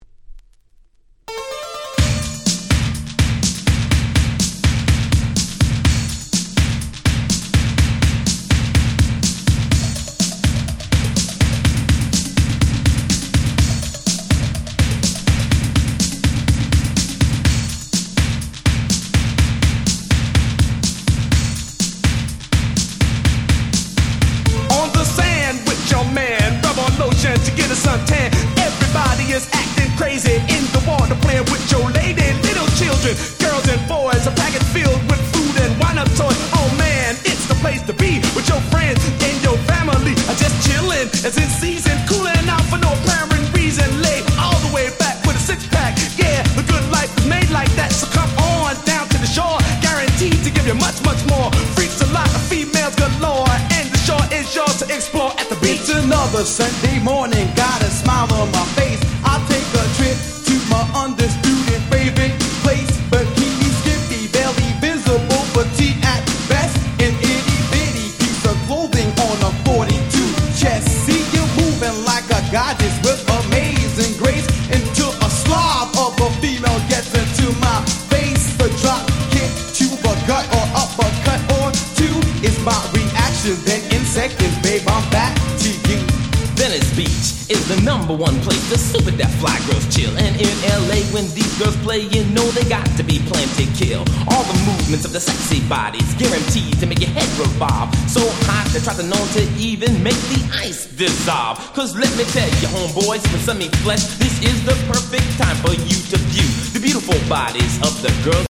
86' Old School Hip Hop Classic !!